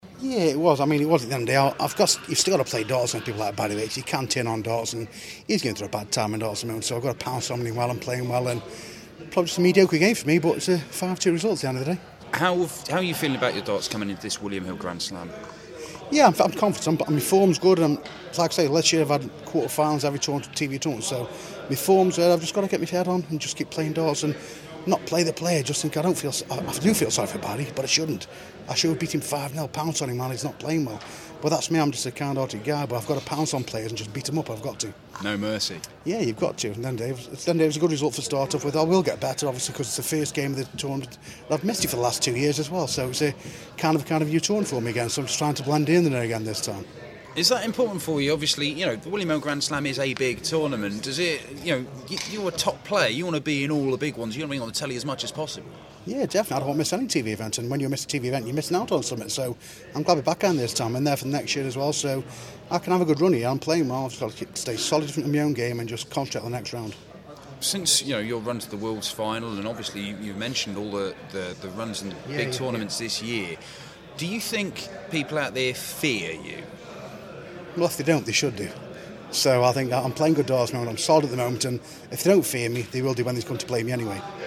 William Hill GSOD - Hamilton Interview